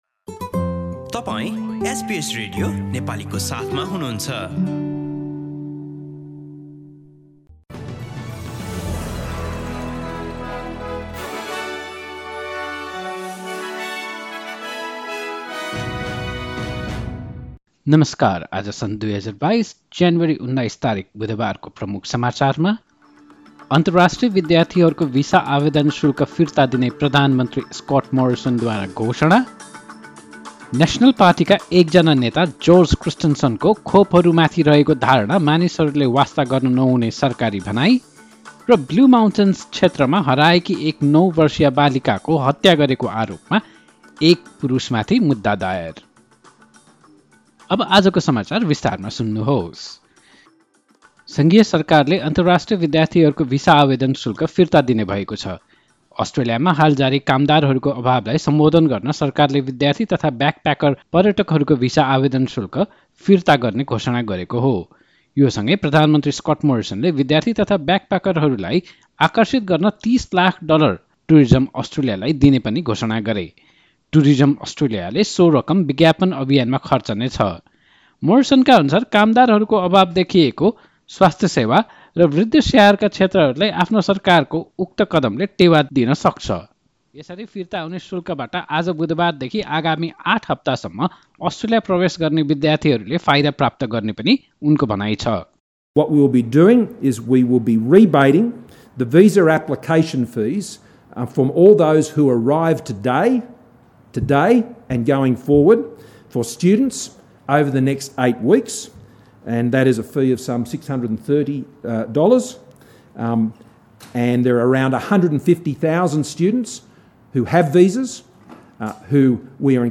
एसबीएस नेपाली अस्ट्रेलिया समाचार: बुधवार १९ जनवरी २०२२